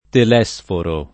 [ tel $S foro ]